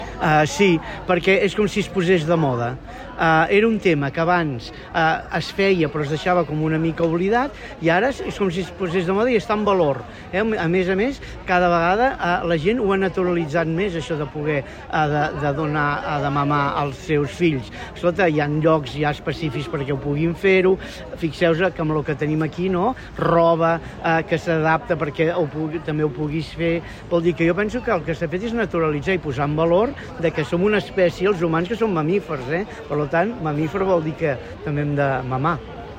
Aquest matí s’ha fet a la plaça de Les Cultures de Martorell la inauguració de la 10a Setmana de la Lactància Materna, que tindrà lloc del 20 al 24 d’octubre a diferents poblacions del Baix Llobregat Nord, entre elles Martorell, amb ponències i activitats per promoure l’alletament.